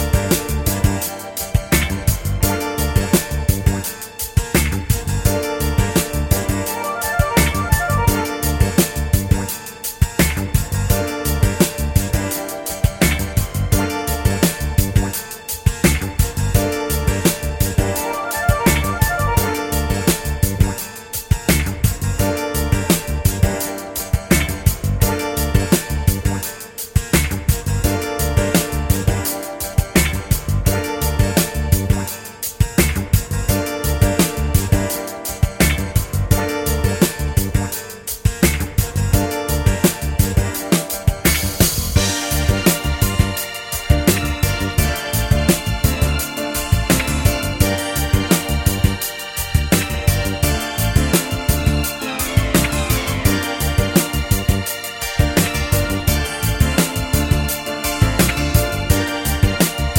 no Backing Vocals Disco 4:19 Buy £1.50